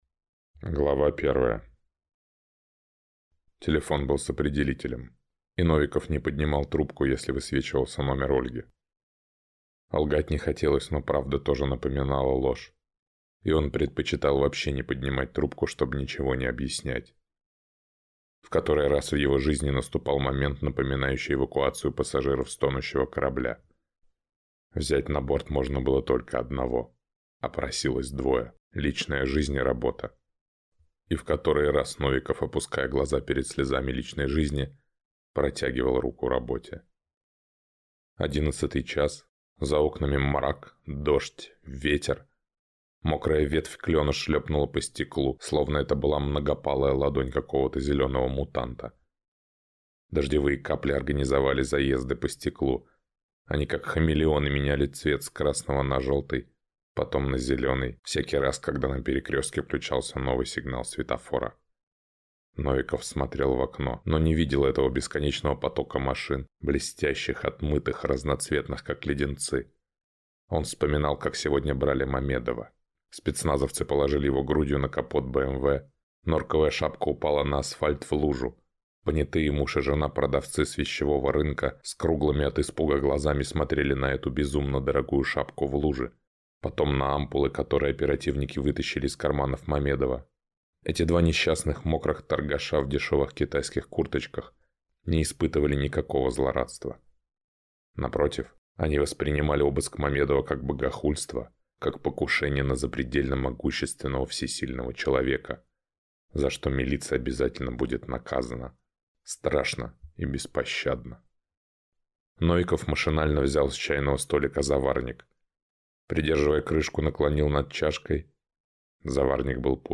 Аудиокнига Вечер гениев | Библиотека аудиокниг